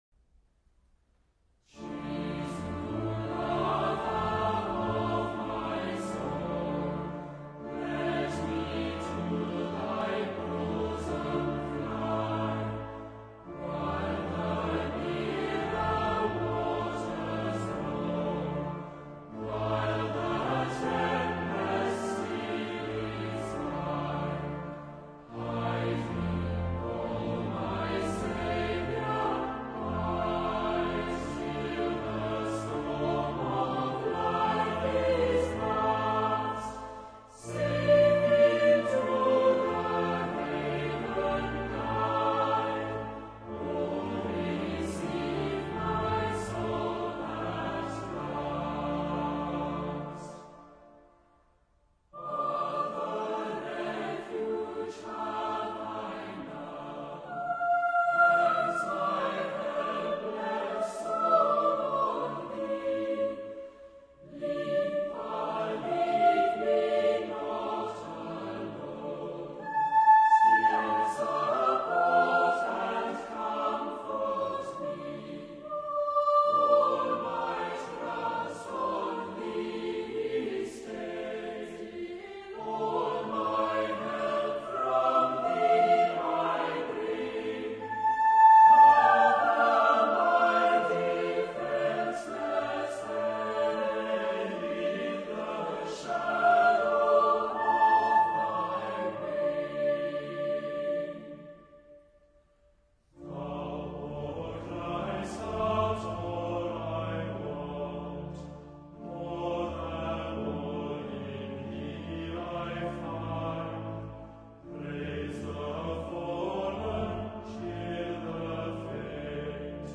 OPENING HYMN  Jesu, Lover of My Soul                Music: Joseph Parry (1879)
Choir of Trinity College, Cambridge
Jesu-Lover-of-my-soul-Trinity-College-Choir-Cambridge.mp3